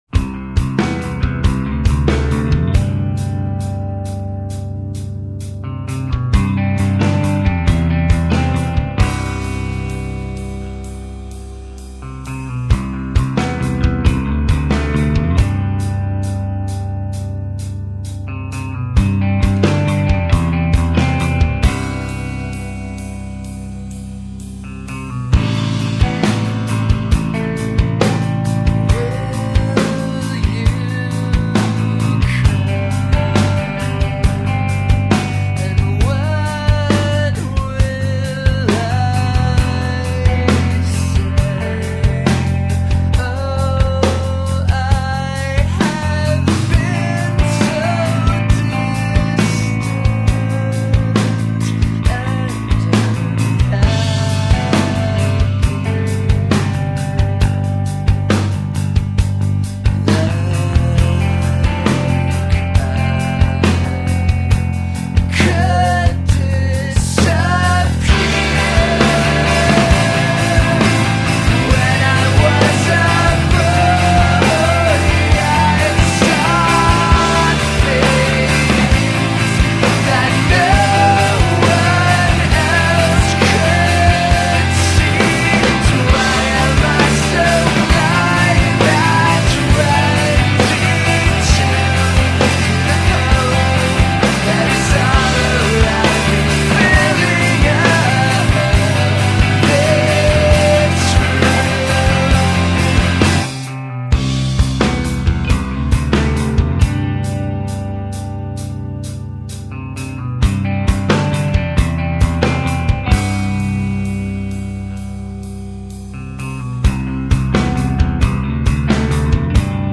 drums
bass guitar